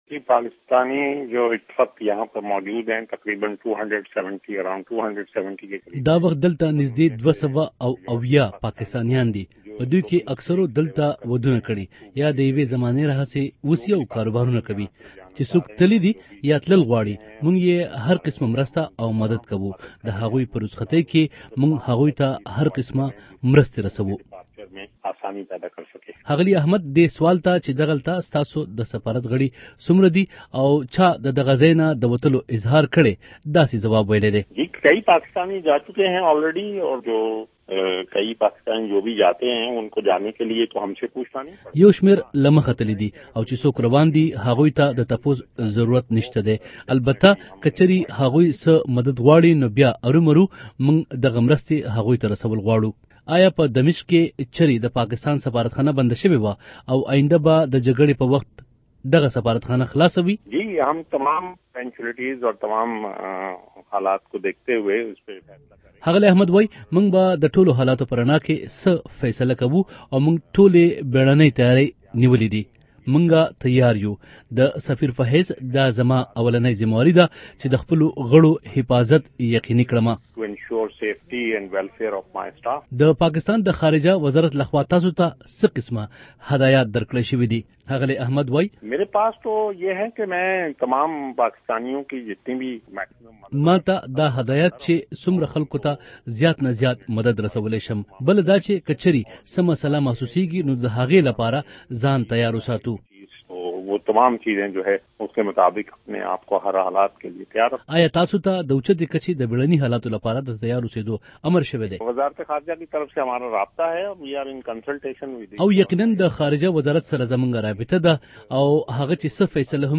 ښاغلي احمد ډيوه رېډېو ته د دمشق نه په ټيلي فون وېلي د شام نه ېو شمیر پاکستانیان وتلي او چې کوم تلل غواړي دوېي ورسره مرستې کوي. واحد احمد زیاته کړی د خپل هیواد خارجه وزارت سره په رابطه کې دی خو تر اوسه د سفارت د بندېدو یا خلاص ساتلو څه فیصله نه ده شوی